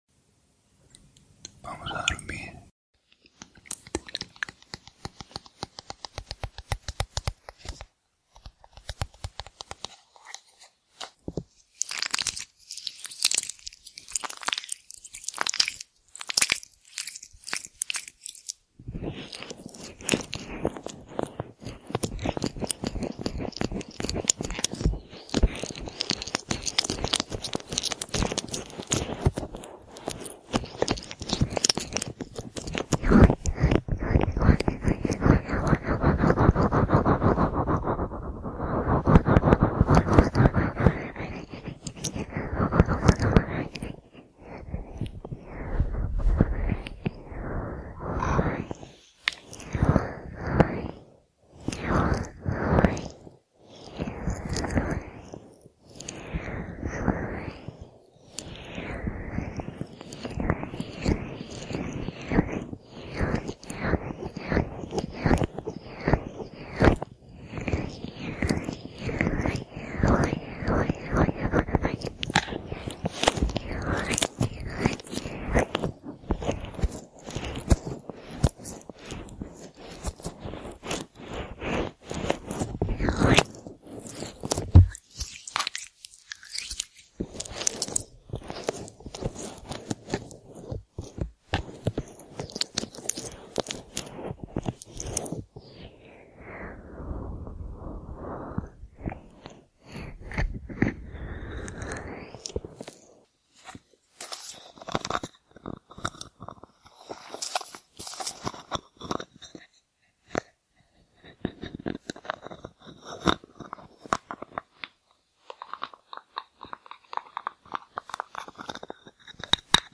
ASMR para dormir - Masaje de orejas